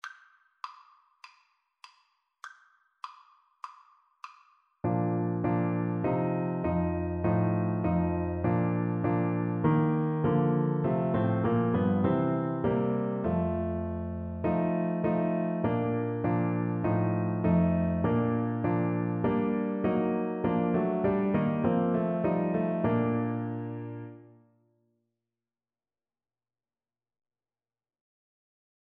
Alto Saxophone
4/4 (View more 4/4 Music)
Classical (View more Classical Saxophone Music)